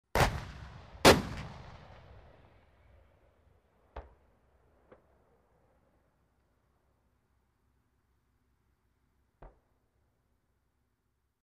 Стрельба из танковой пушки и пулемётного орудия в mp3 формате
5. Танк стреляет
Vystrel-tanka-6.mp3